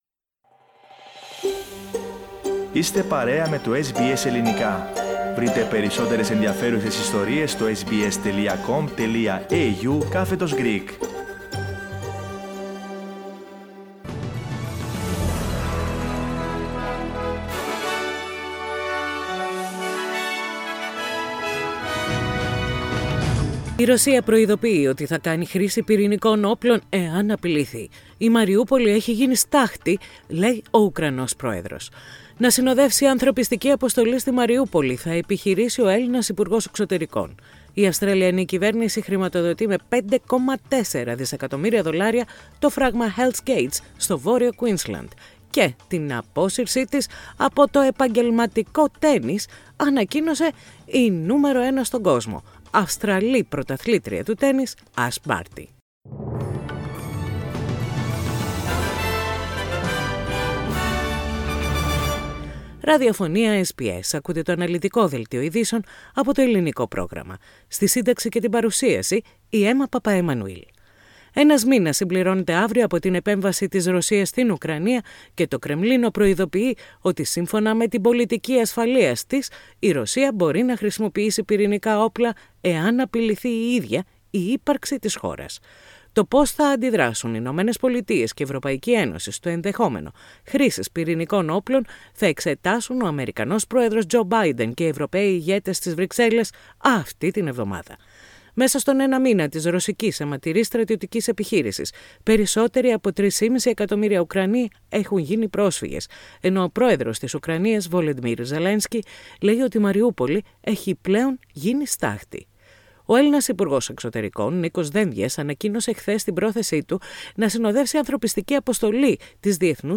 Δελτίο Ειδήσεων - Τετάρτη 23.3.22
Το αναλυτικό δελτίο ειδήσεων με τα κυριότερα νέα από όλο τον κόσμο, την Αυστραλία, την Ελλάδα και την Κύπρο.
News in Greek. Source: SBS Radio